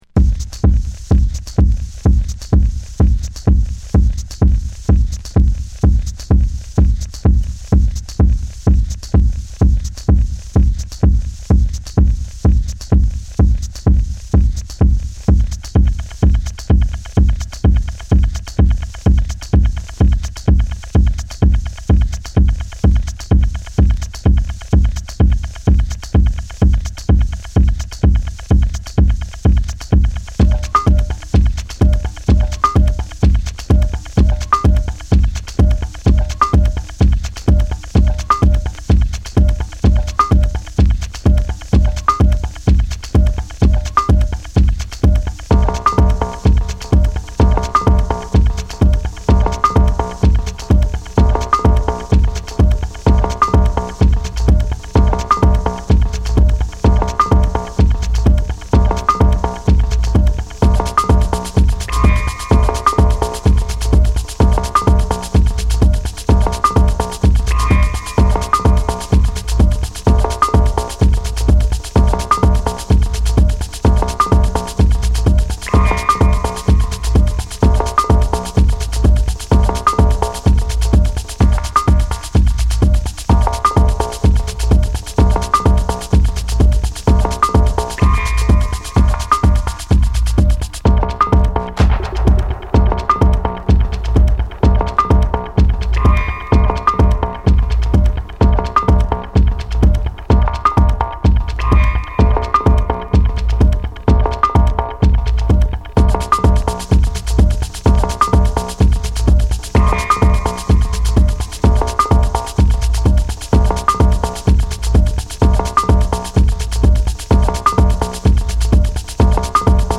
パーカッションと感電したようなベースラインでじわじわとハメるBass Musicとしても機能する